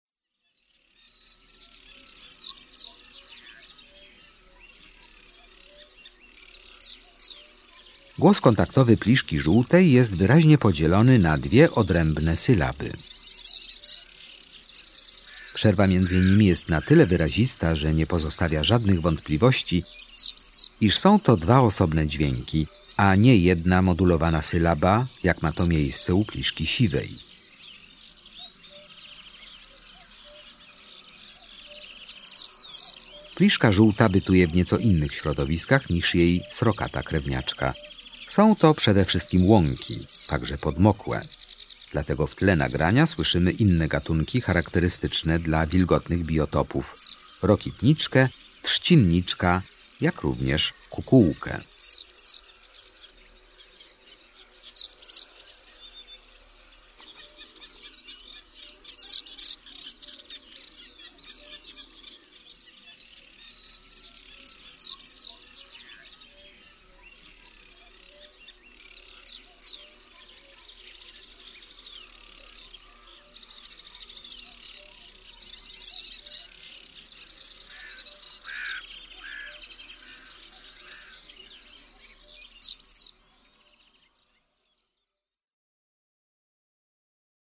16 Pliszka żółta.mp3